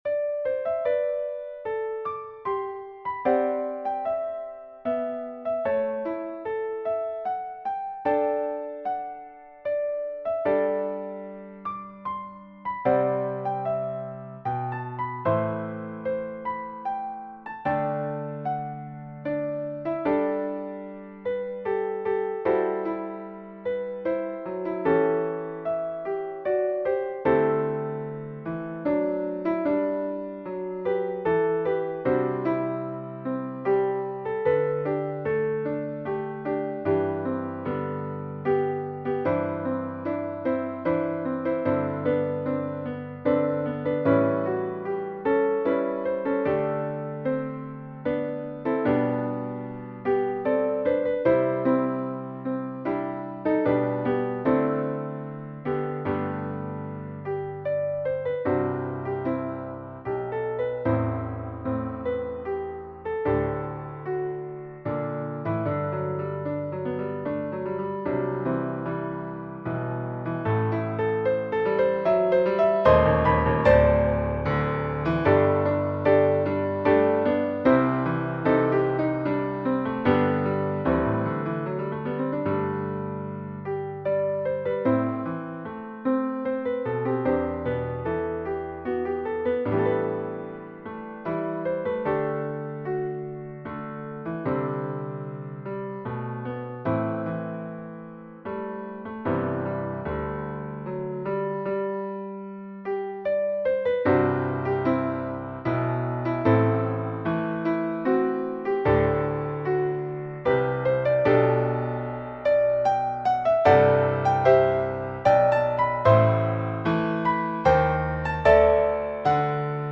SATB
Brightly Beams Our Father's Mercy for SATB with piano accompaniment. It is HYMPLICITY style with SATB parts straight out of the hymn book.